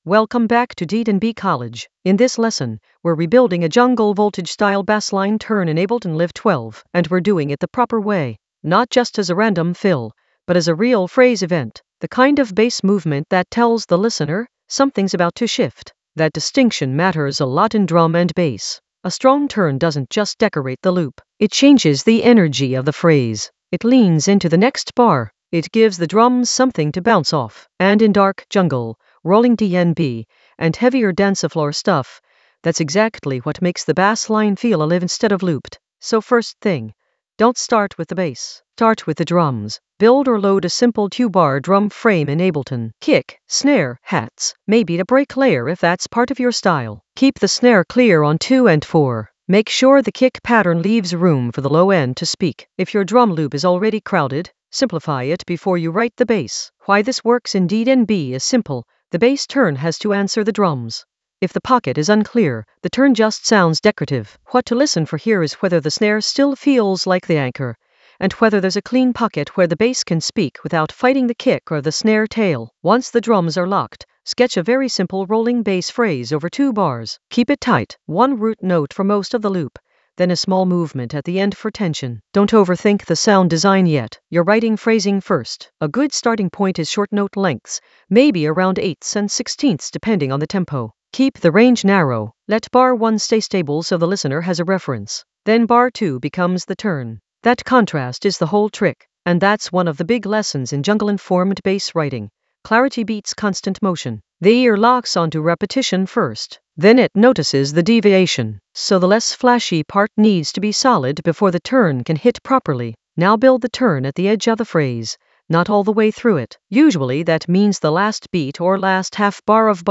Narrated lesson audio
The voice track includes the tutorial plus extra teacher commentary.
An AI-generated advanced Ableton lesson focused on Jungle Voltage approach: a bassline turn rebuild in Ableton Live 12 in the Edits area of drum and bass production.